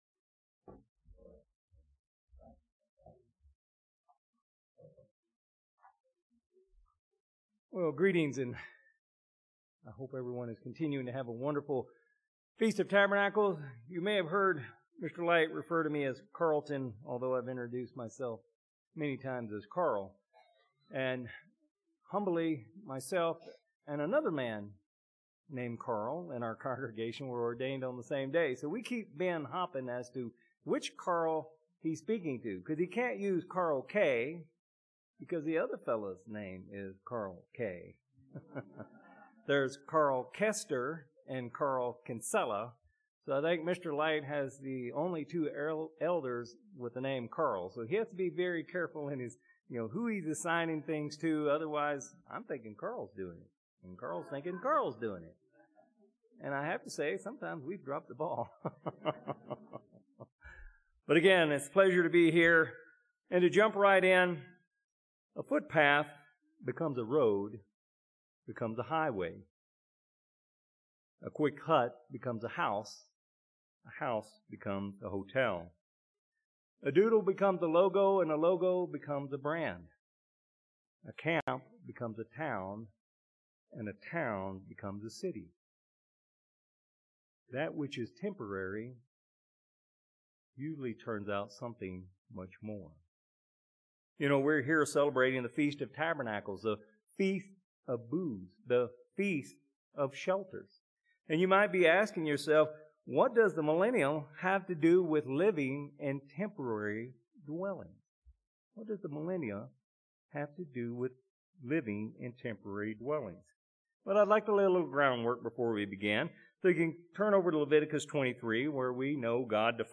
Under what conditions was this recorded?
This sermon was given at the Klamath Falls, Oregon 2024 Feast site.